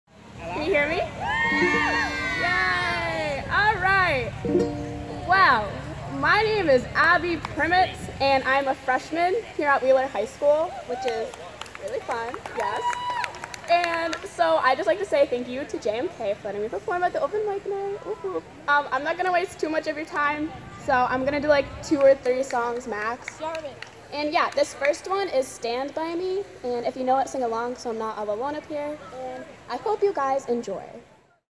This music was recorded at the North Stonington Middle School-High School grounds in North Stonington, CT using an Android Galaxy 7 Edge on September 23rd, 12:00PM-4:00PM.